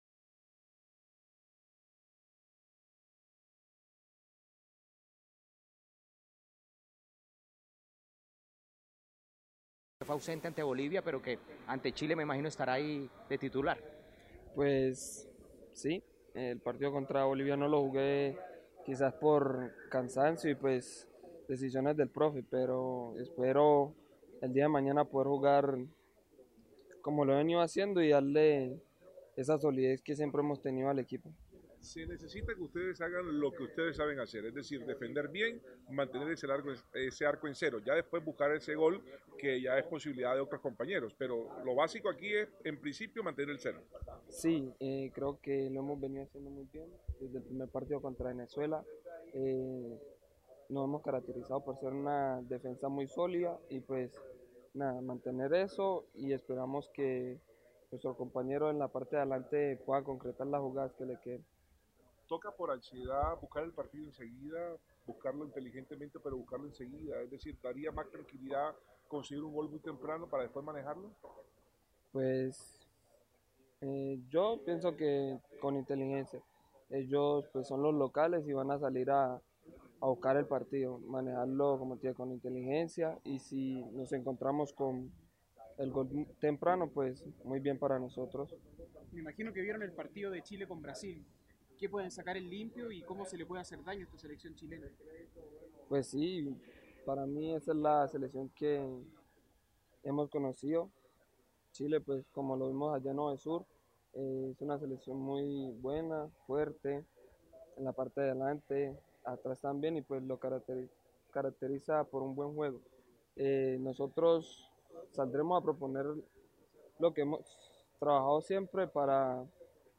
Luego del entrenamiento, cuatro jugadores atendieron a la prensa en su concentración: